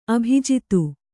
♪ abhijitu